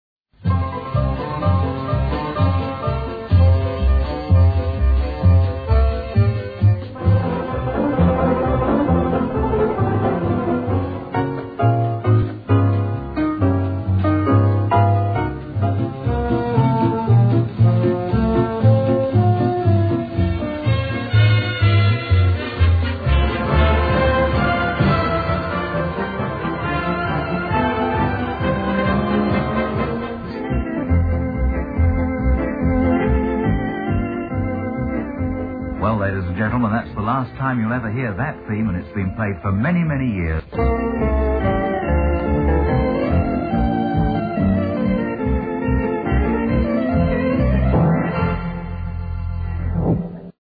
A Medley of intro's